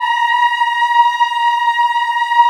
A#4 WOM AH-L.wav